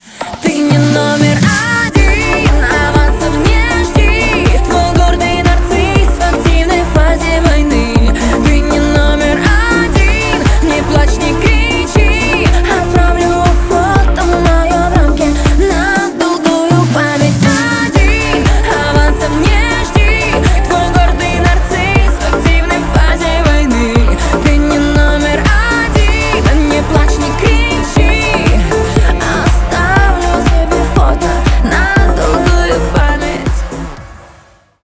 Stereo
Поп
клубные громкие